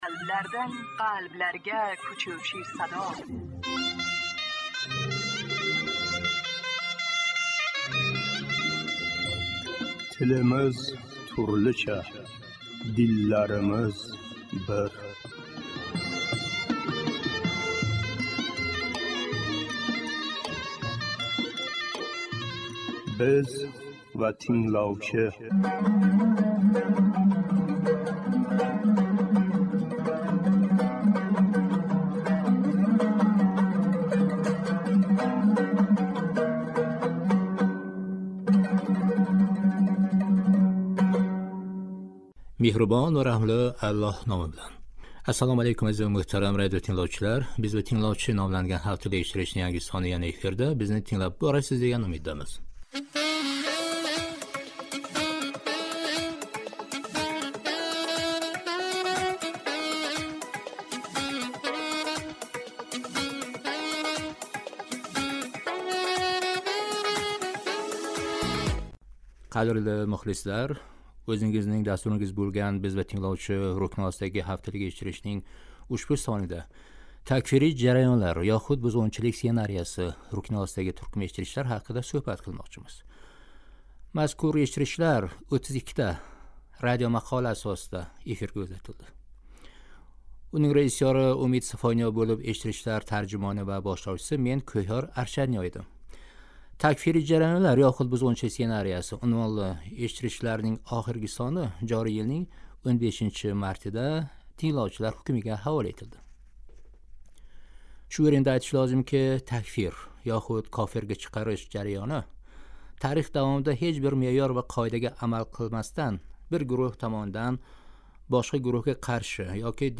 Тингловчилар сўҳбати